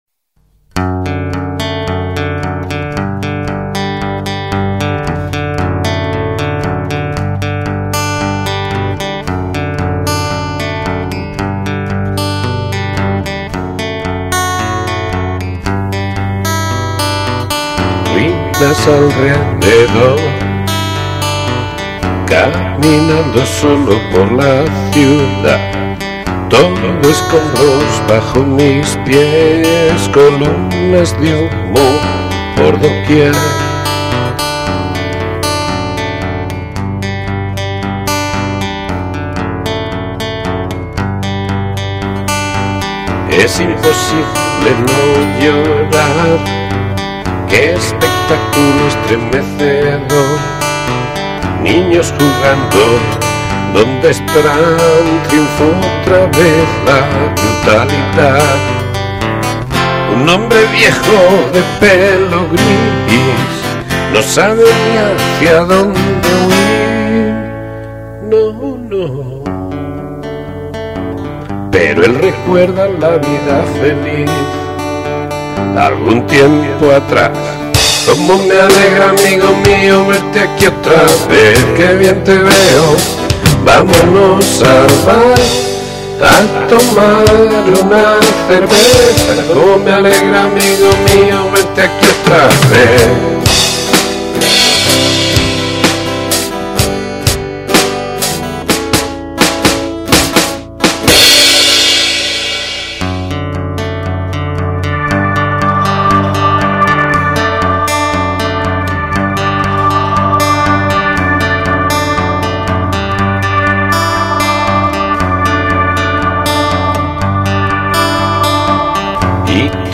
Voice, acoustic guitar, synthesizer and drum programming.